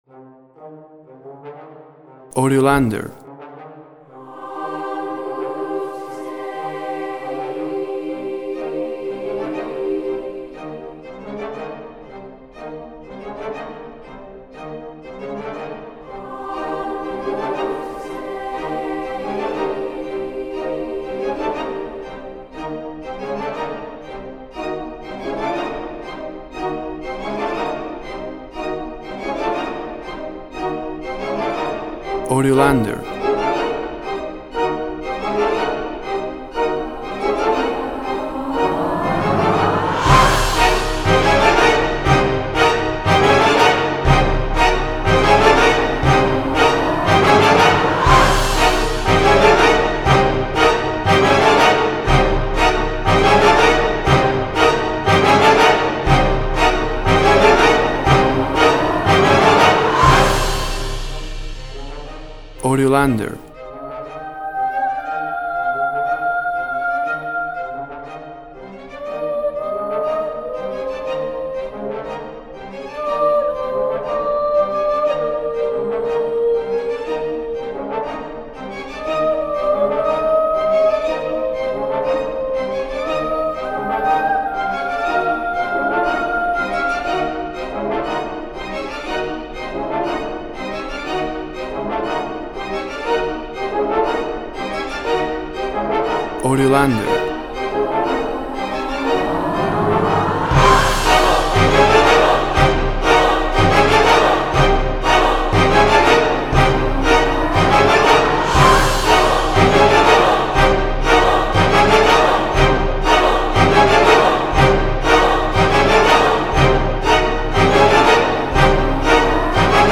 Tempo (BPM) 80